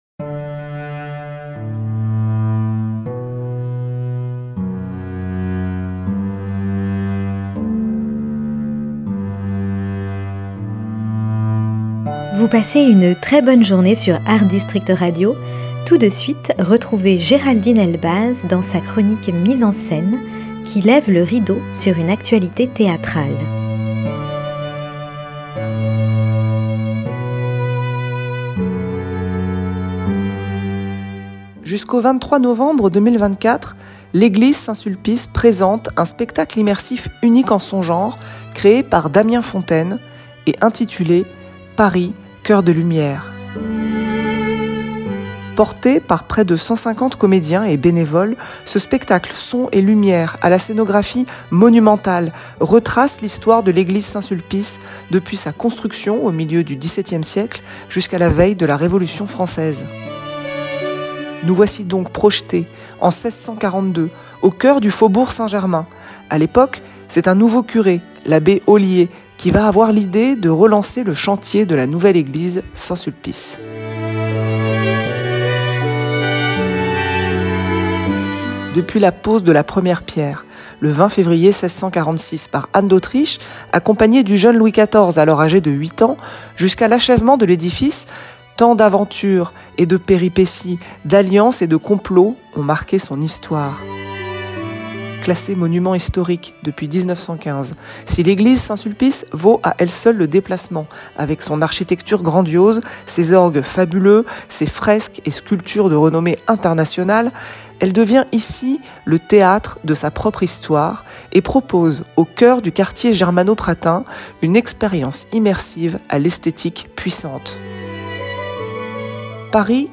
Chronique théâtrale